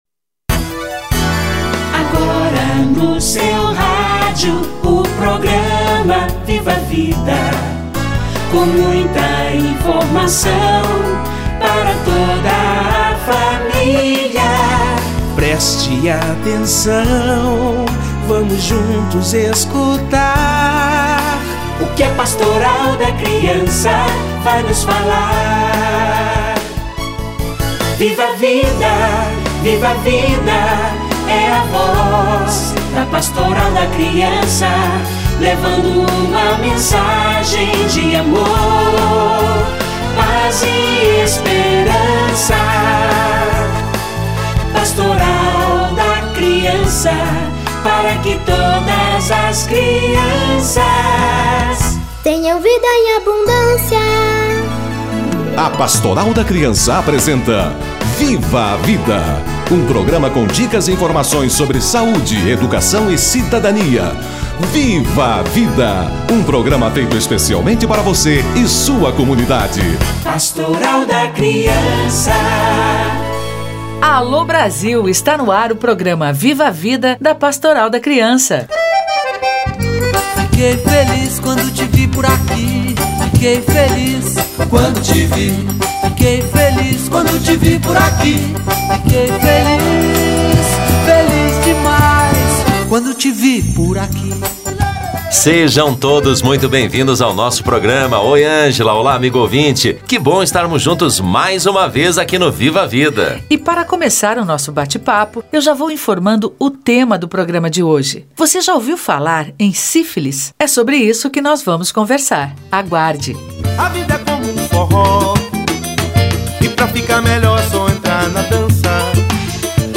Sífilis e doenças negligenciadas - Entrevista